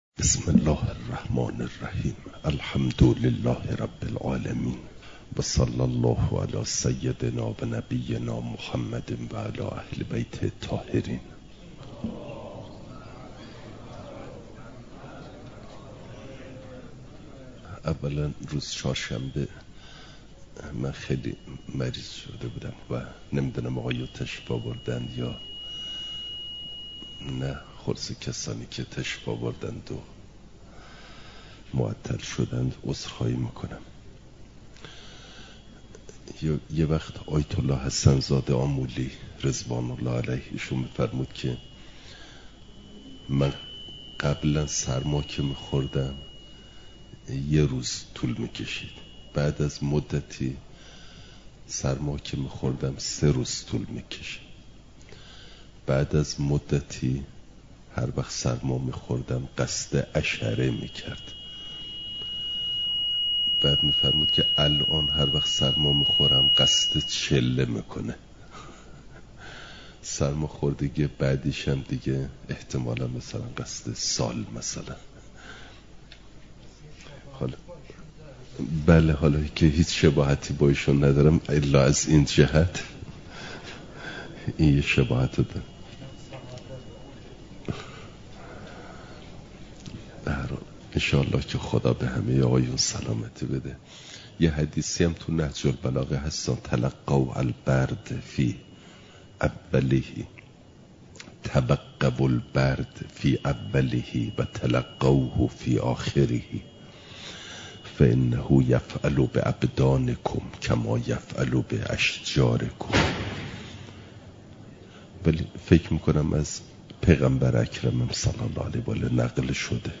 مسائل مستحدثه قضا (جلسه۶۱) « دروس استاد